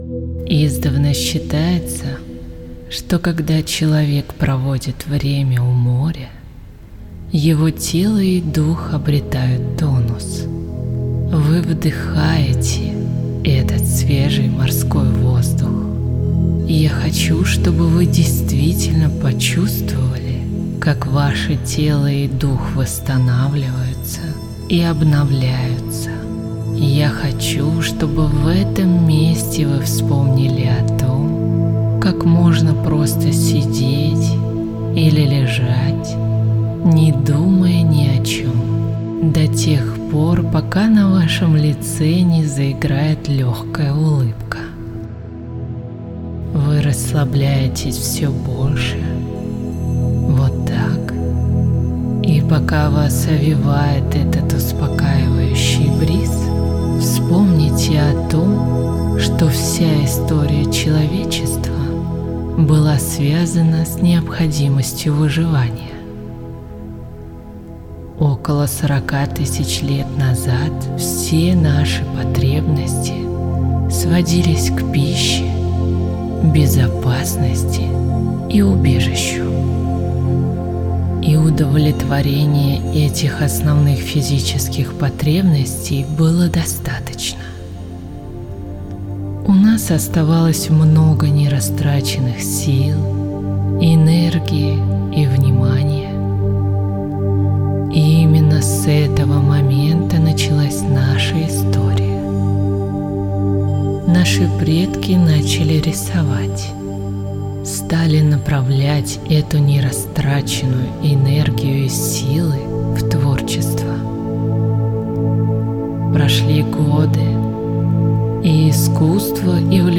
Создайте моменты спокойствия в вашем дне с помощью расслабляющего сеанса гипноза.
Отрывок_Гипноз_Уменьшение_ежедневного_стресса.mp3